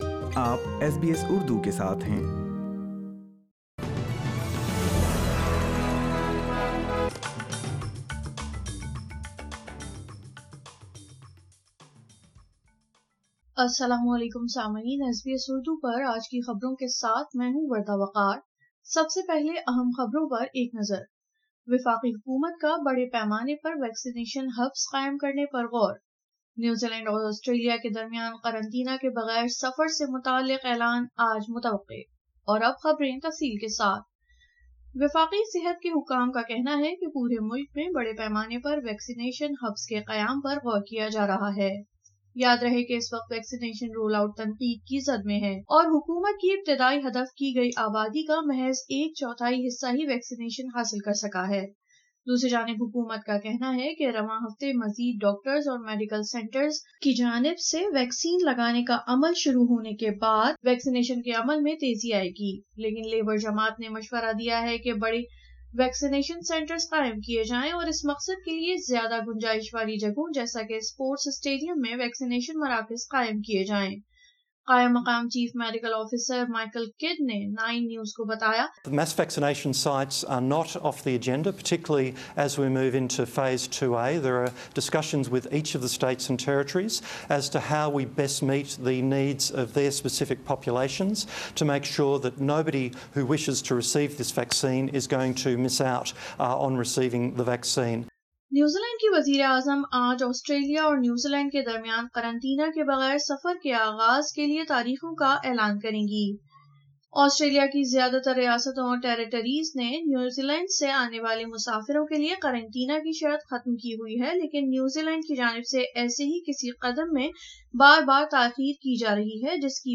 اردو خبریں 06 اپریل 2021